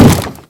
sounds / mob / zombie / wood3.ogg
wood3.ogg